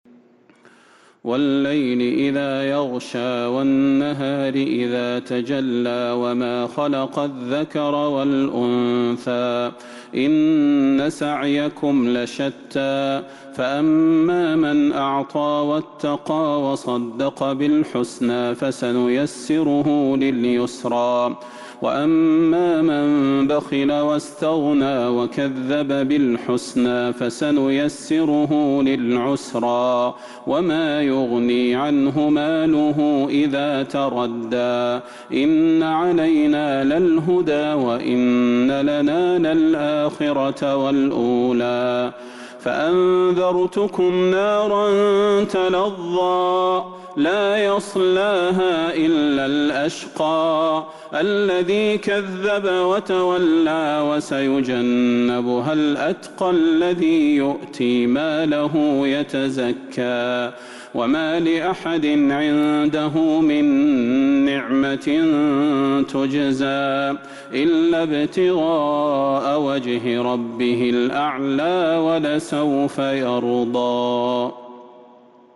سورة الليل Surat Al-Layl من تراويح المسجد النبوي 1442هـ > مصحف تراويح الحرم النبوي عام ١٤٤٢ > المصحف - تلاوات الحرمين